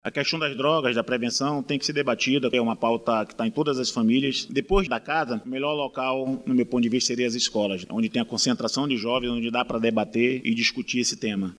A prevenção e o combate às drogas nas escolas municipais de Manaus foram colocados em debate, durante o Grande Expediente da Câmara Municipal de Manaus – CMM, desta terça-feira 24/02. O vereador Allan Campelo, do Podemos, usou a tribuna da Casa Legislativa para defender ações educativas dentro das Unidades de Ensino da capital.